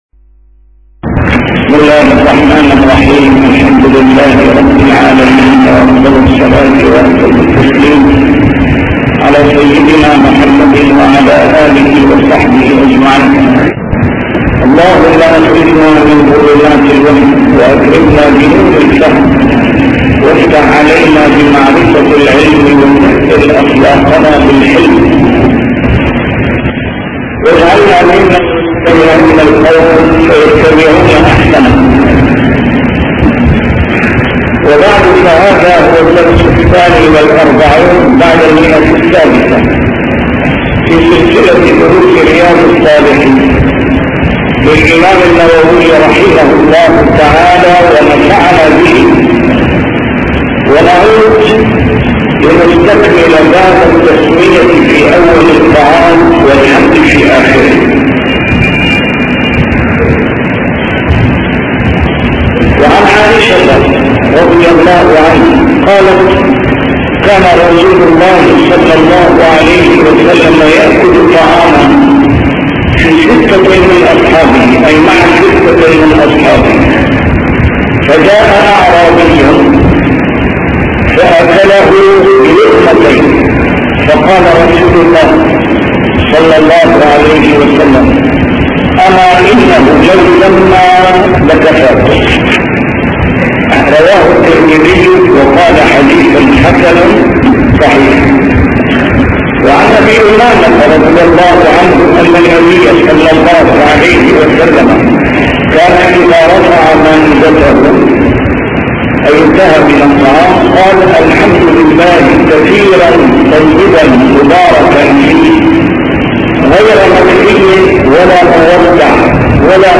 نسيم الشام › A MARTYR SCHOLAR: IMAM MUHAMMAD SAEED RAMADAN AL-BOUTI - الدروس العلمية - شرح كتاب رياض الصالحين - 642- شرح رياض الصالحين: التسمية في أول الطعام والحمد في آخره